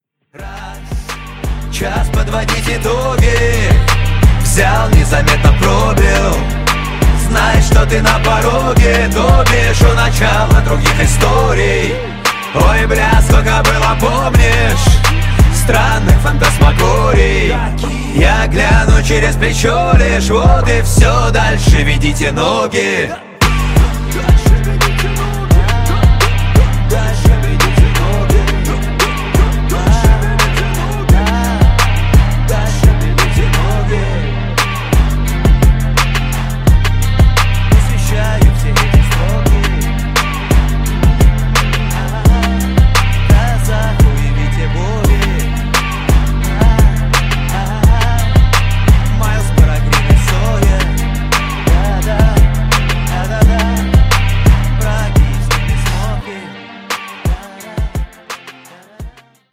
• Качество: 320, Stereo
русский рэп
качающие